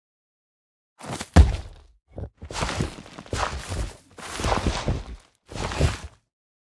Minecraft Version Minecraft Version 25w18a Latest Release | Latest Snapshot 25w18a / assets / minecraft / sounds / mob / sniffer / longdig2.ogg Compare With Compare With Latest Release | Latest Snapshot
longdig2.ogg